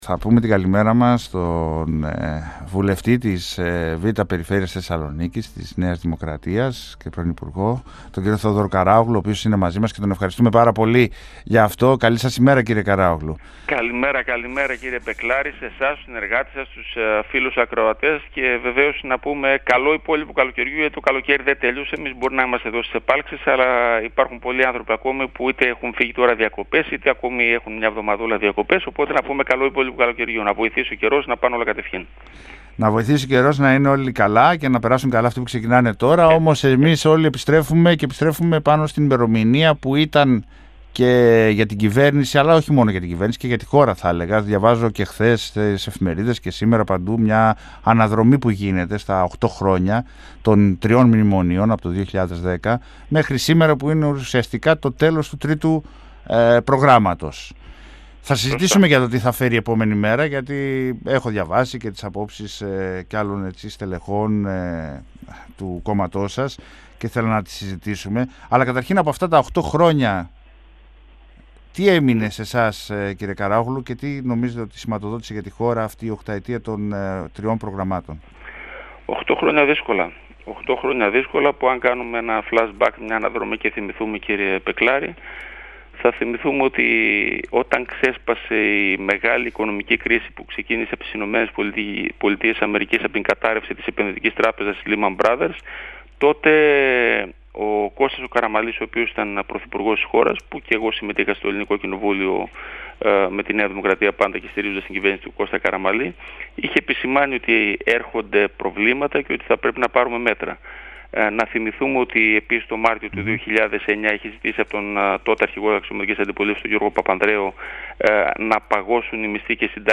Ο βουλευτής Β΄ Θεσσαλονίκης της ΝΔ Θεόδωρος Καράογλου μίλησε στην εκπομπή Πολιτικό Ημερολόγιο του 102FM της ΕΡΤ3